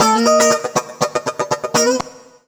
120FUNKY16.wav